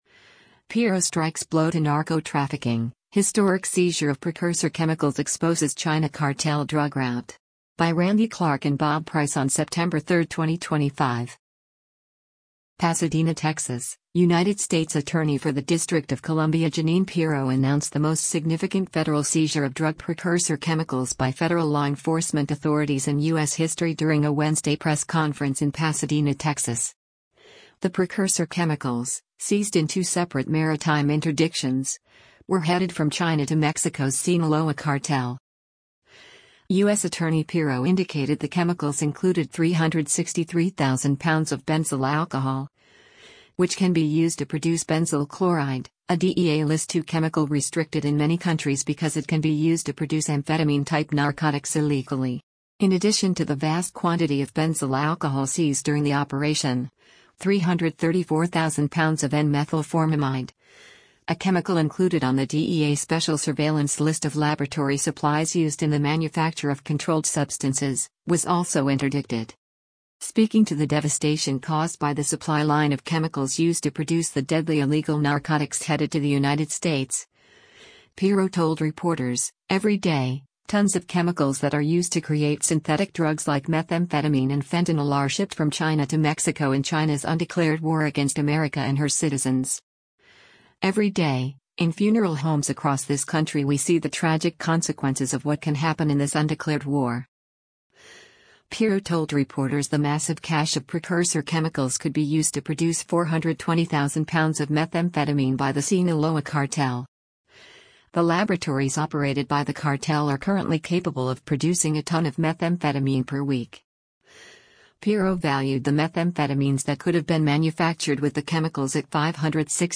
PASADENA, Texas — United States Attorney for the District of Columbia Jeanine Pirro announced the most significant federal seizure of drug precursor chemicals by federal law enforcement authorities in U.S. history during a Wednesday press conference in Pasadena, Texas.